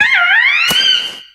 Audio / SE / Cries / CHIMCHAR.ogg
CHIMCHAR.ogg